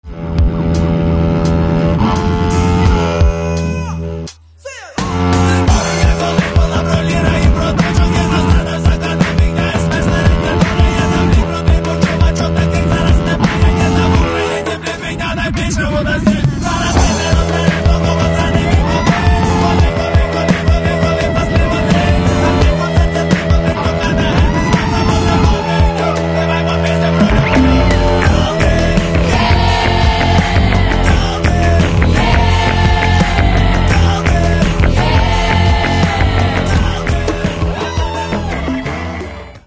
Catalogue -> Rock & Alternative -> Folk Rock